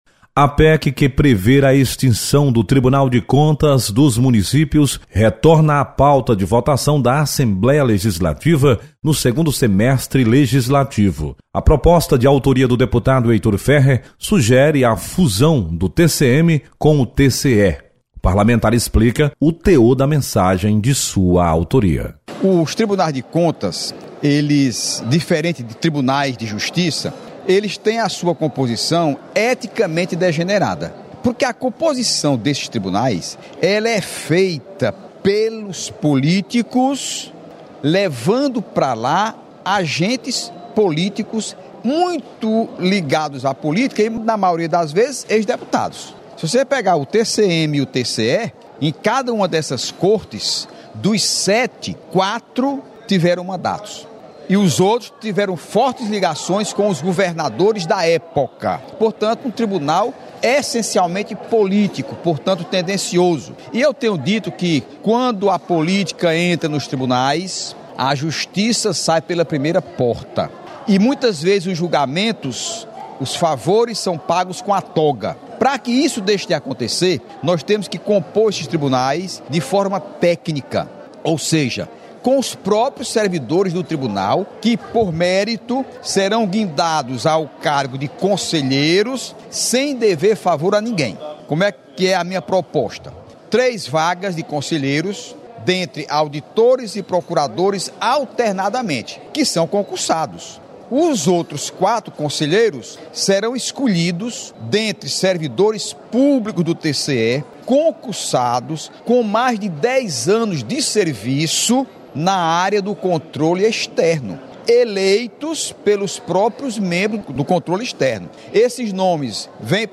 Pec do TCM retorna à pauta de votação da Assembleia neste semestre. Repórter